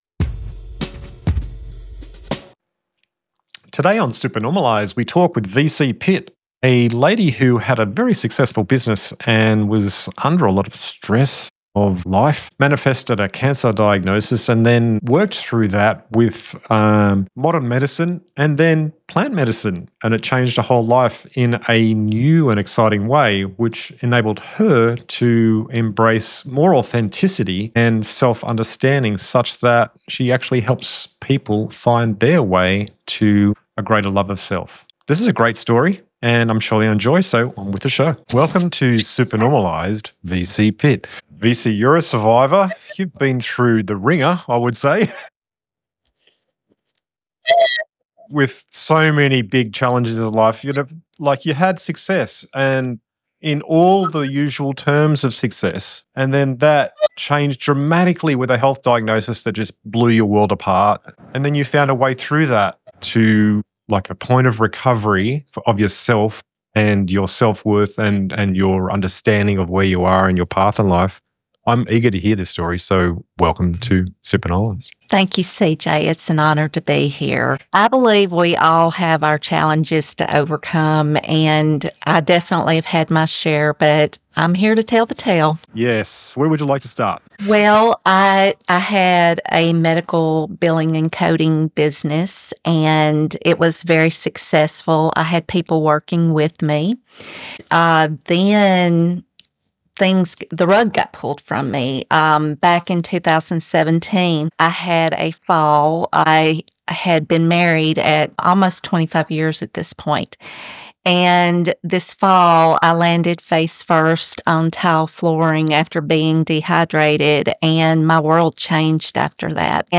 Interview Can Tobacco Shamanism Clear Deep Trauma?